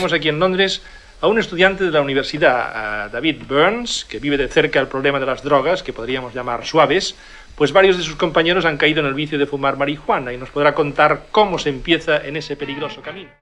Presentció d'un estudiant que viu a Londres per parlar de les drogues suaus